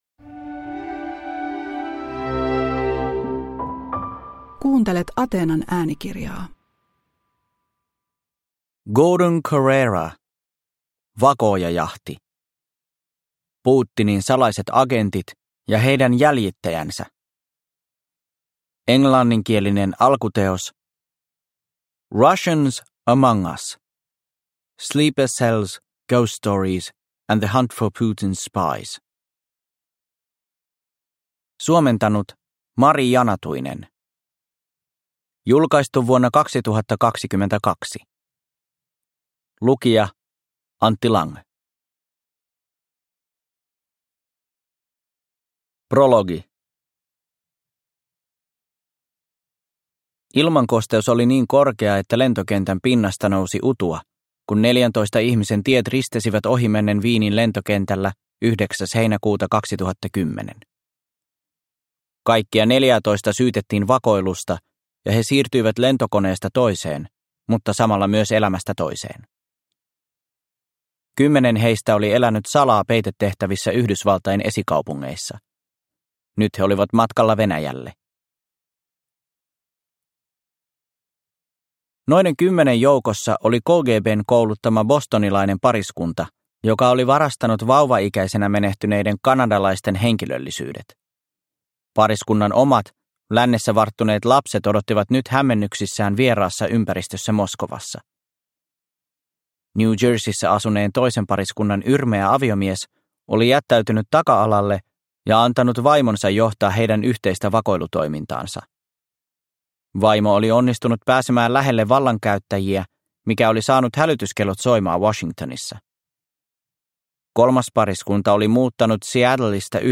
Vakoojajahti – Ljudbok – Laddas ner